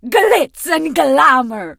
diva_ulti_vo_02.ogg